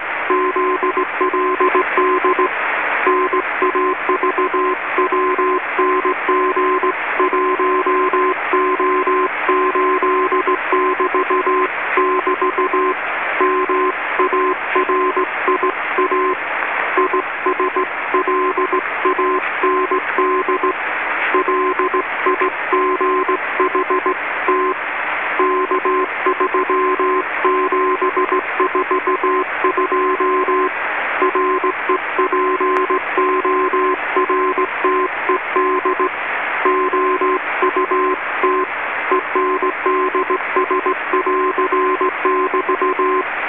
Some historic sounds mainly of New Zealand marine radiotelegraphy from 1993.
All the recordings were done while the ARAHURA was in the Cook Strait / Wellington / Picton area.
Some of the recordings are of very distant stations such as Suvaradio / 3DP at about 1500 miles, Aucklandradio at about 400 miles and GYSA which was probably at least 400 miles.